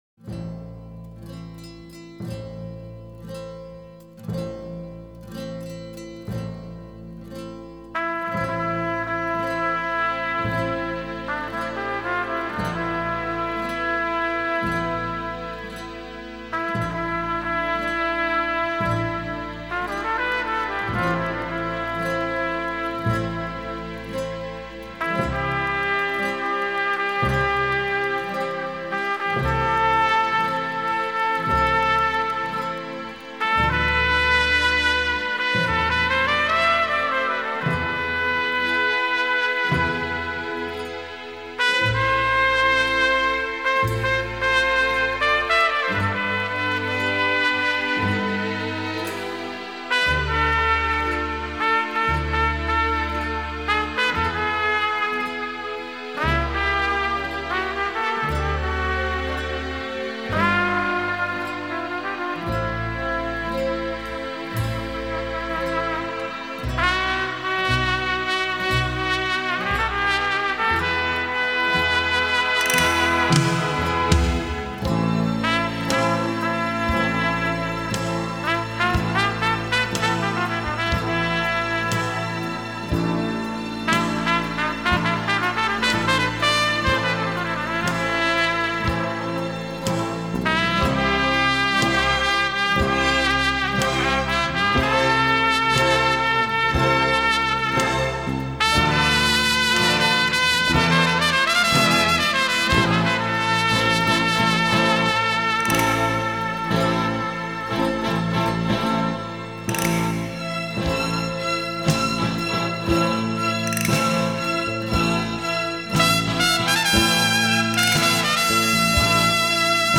играет ровно, без прикрас.